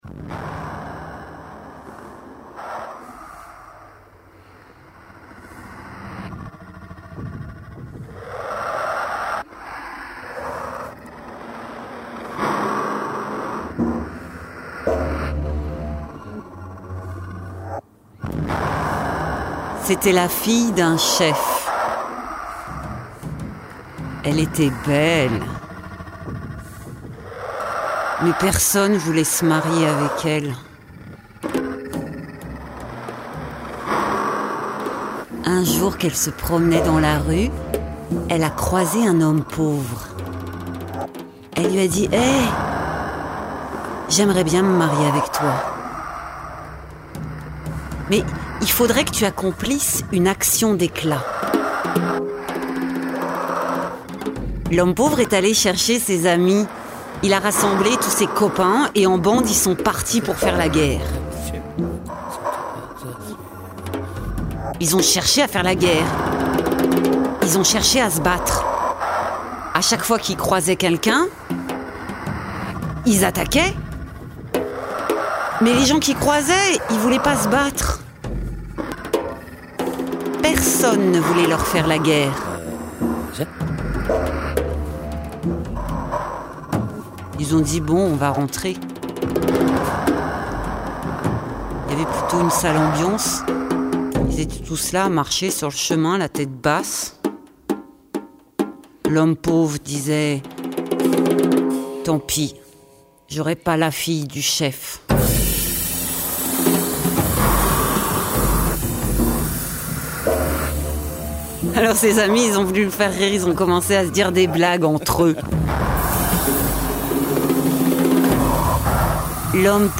2009-09-24 Sept récits pleins de créatures décalées et de destinées improbables par un duo de choc au discours musical inouï. Un album intense et puissant entre le calme et la fureur.